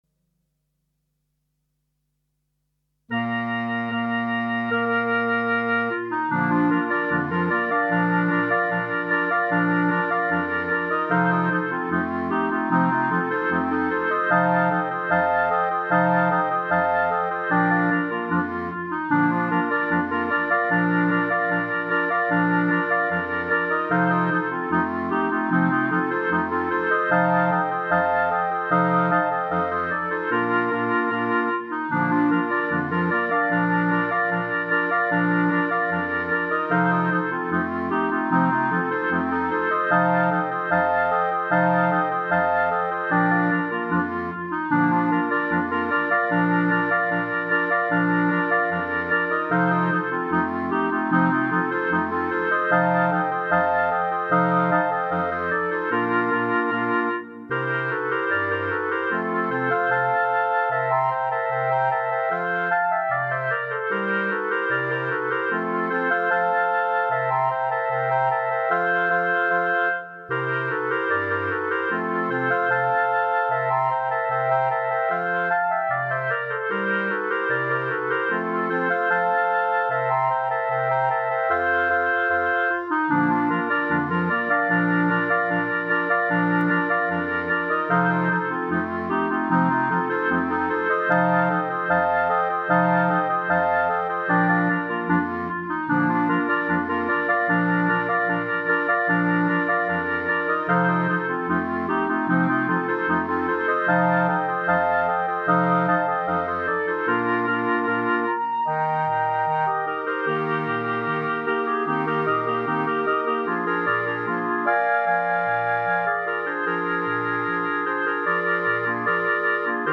Instrumentation:4 Clarinet. opt Bass Cl. Eb Alto Cl,
Bass, Rhythm Gtr.
traditional Polka is arranged for 4 x Clarinet with
optional Bass Clarinet, Eb Alto Clarinet + bass part
with guitar chords.